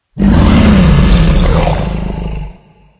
growl3.ogg